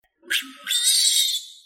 TengTongShiJiaoSheng.mp3